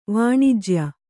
♪ vāṇijya